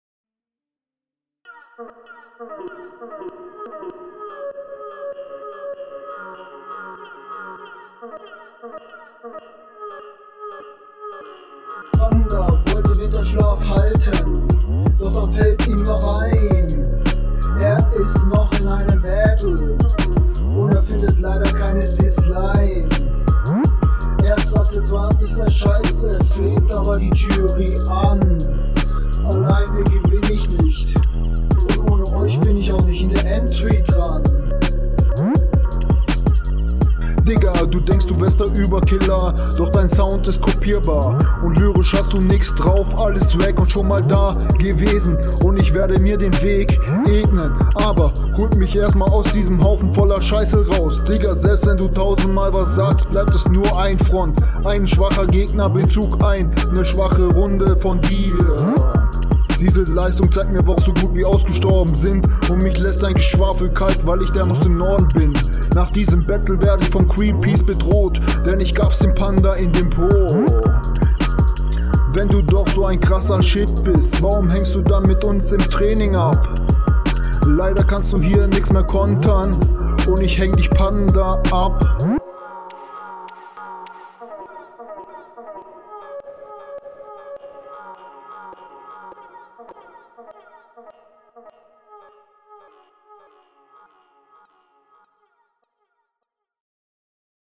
Schwer zu verstehen am Anfang.
Stimme und Flow nicht gut.